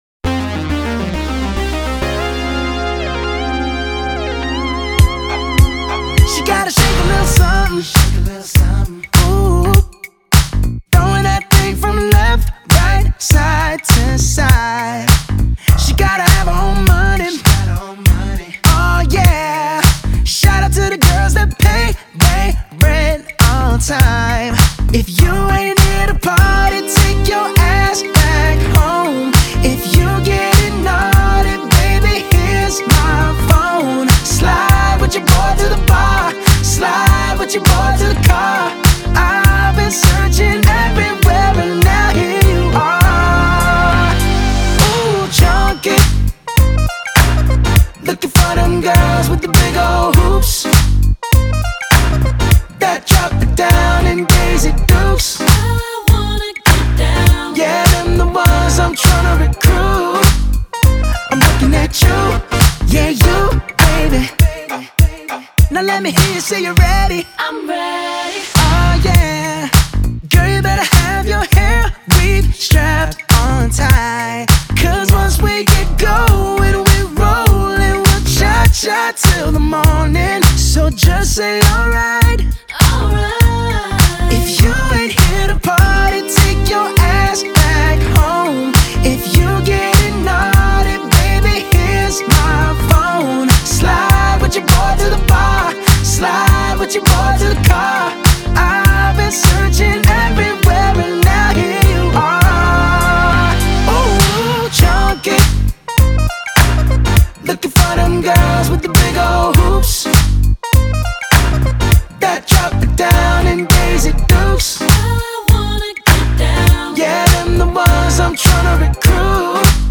Pop/R&B